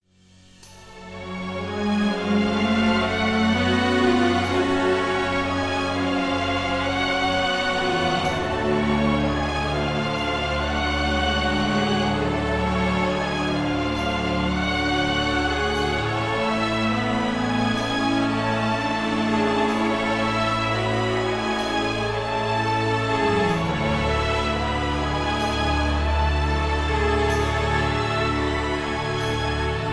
(Key-A)